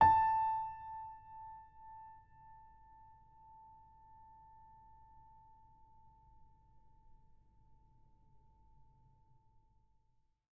sampler example using salamander grand piano
A5.ogg